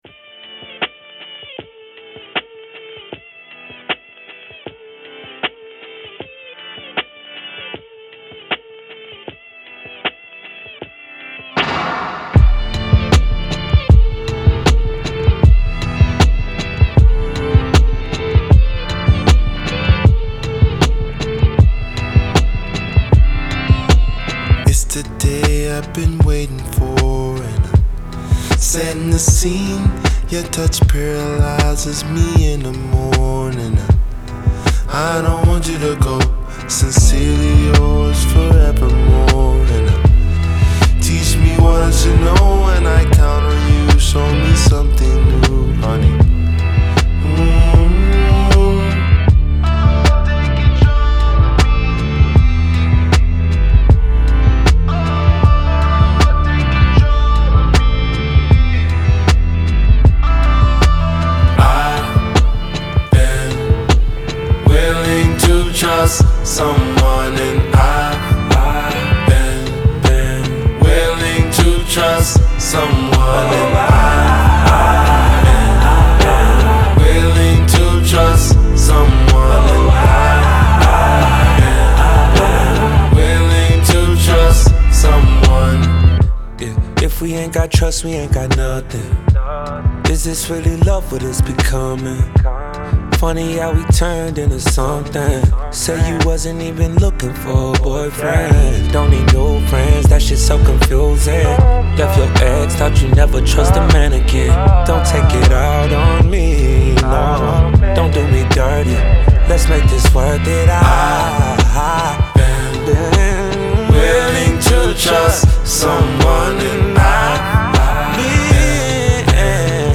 Genre : R&B